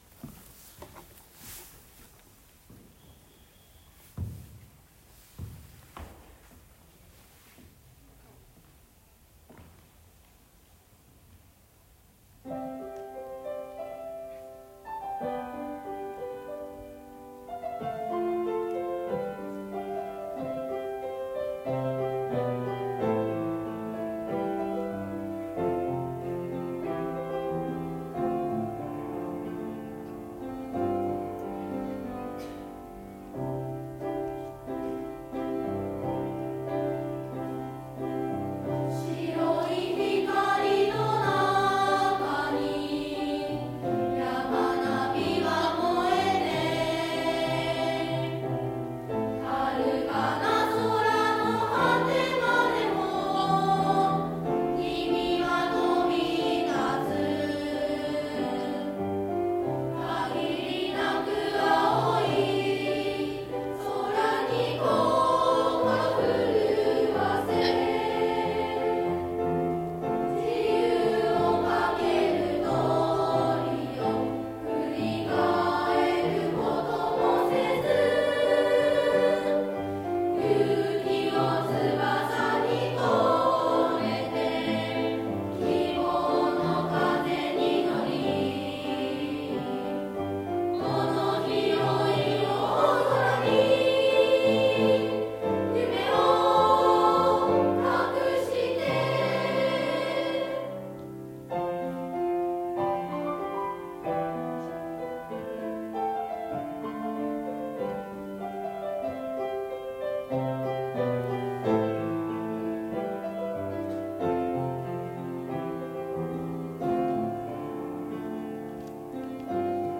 卒業式で流した
「旅立ちの日に」と同様、ほとんど練習もできませんでしたが、気持ちのこもった美しい歌声でした（いずれも録音）。
BGMは、卒業生が録音した「旅立ちの日に」です。